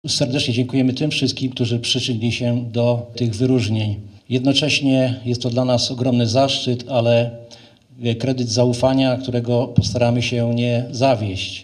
uroczystość odznaczania zasłużonych strażaków